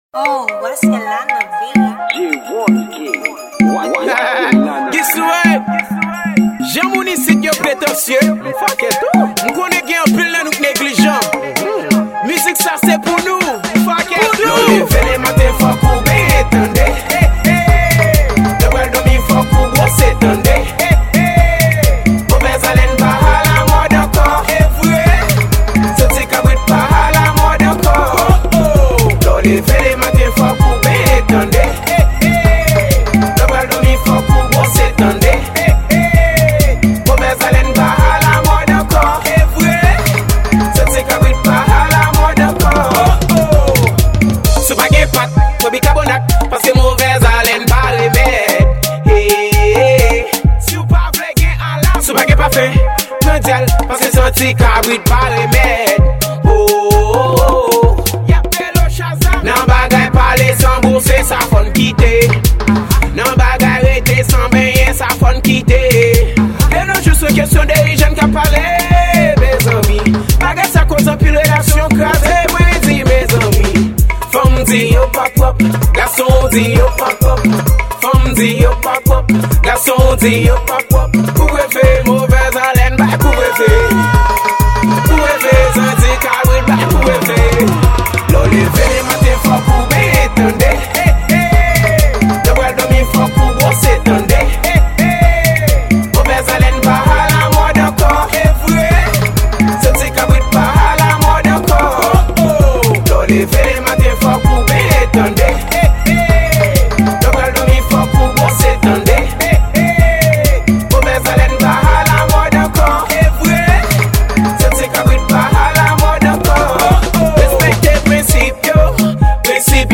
Genre: Afro.